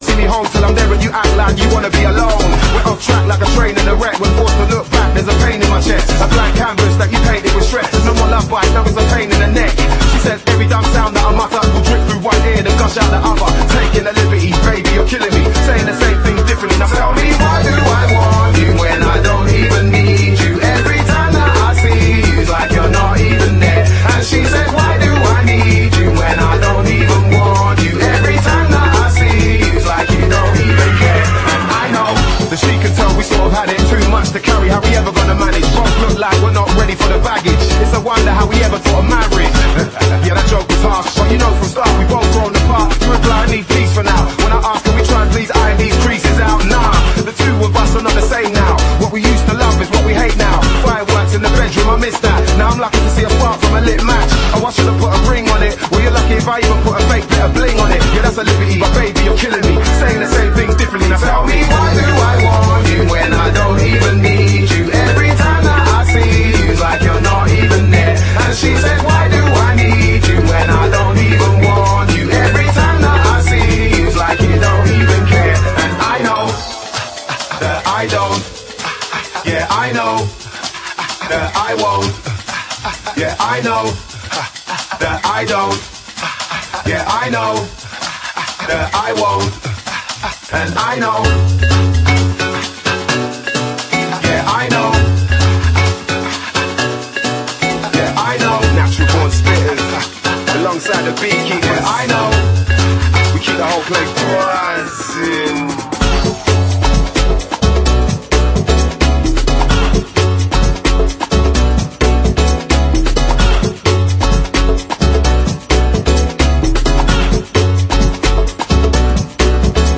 funky, 90s styled rave-up